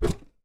Punching Box Normal B.wav